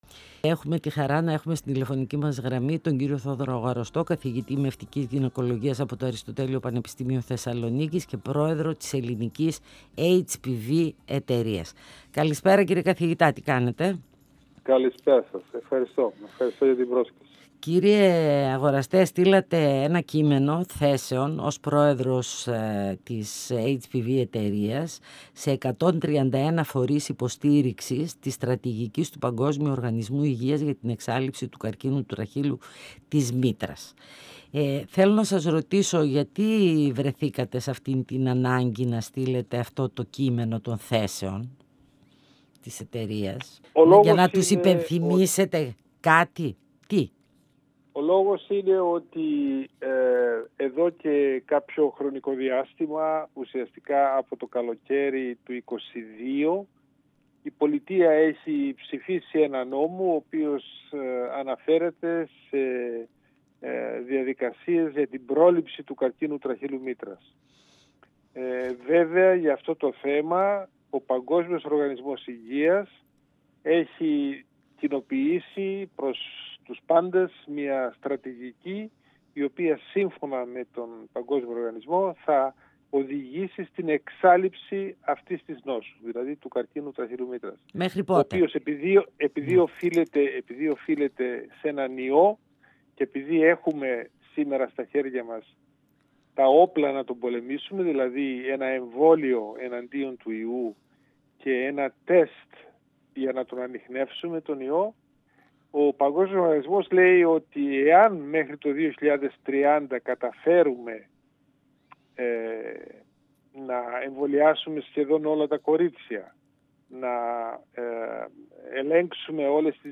Φωνες Πισω απο τη Μασκα Συνεντεύξεις